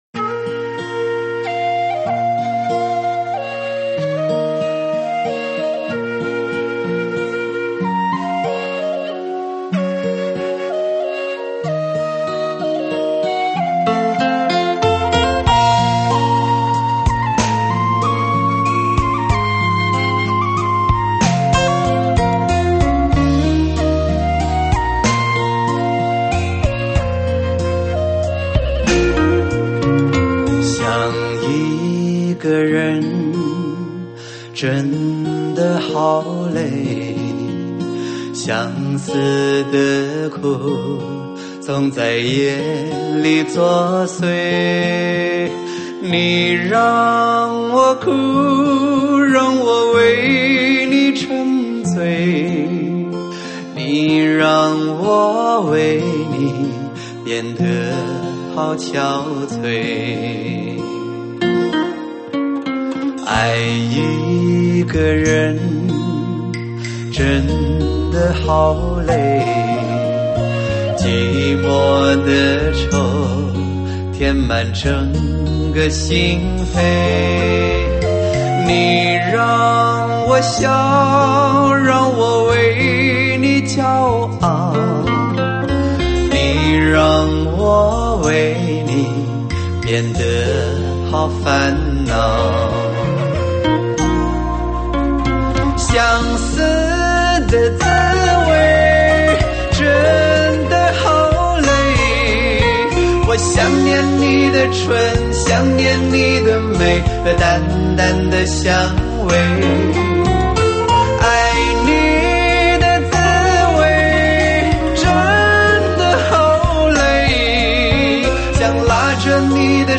无与伦比的极致人声 让心灵无限释放
顶级HI-FI试音人声